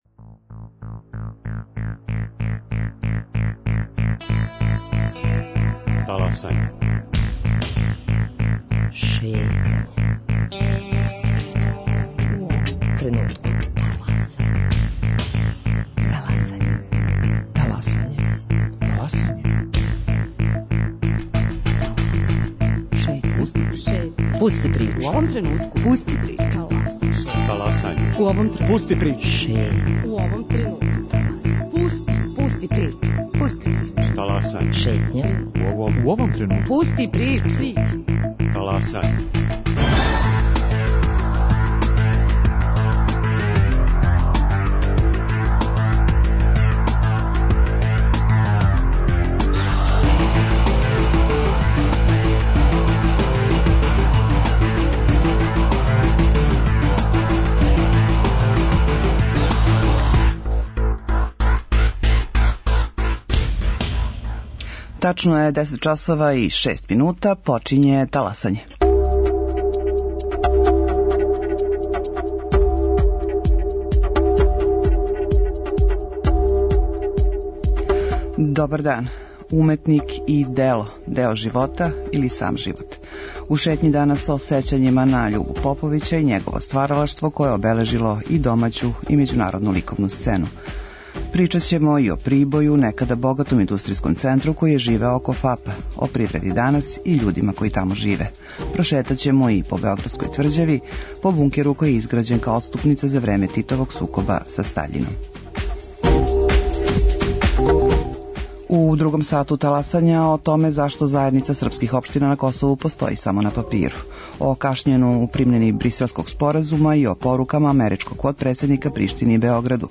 Radio Beograd 1, 10.05